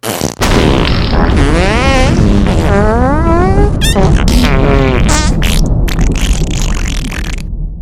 mvm_bomb_explode.wav